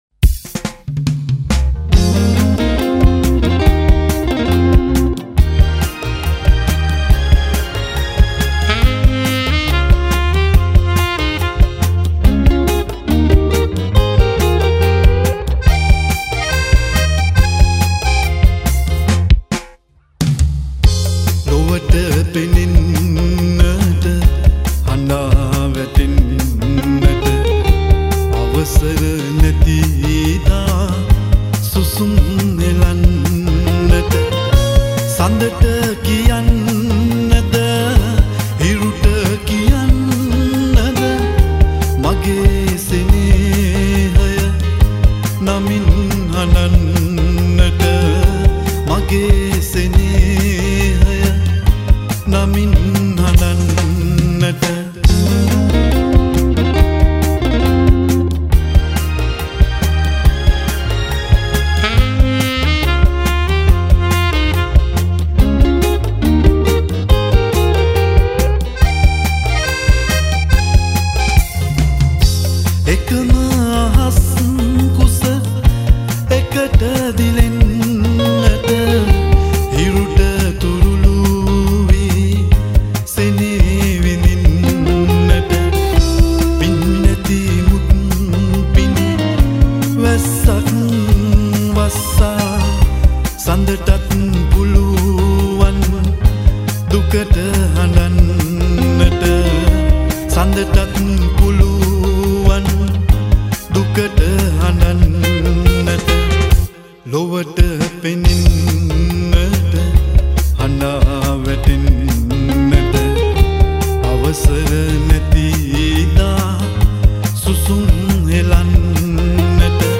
at Sumeega Studio Panadura Sri Lanka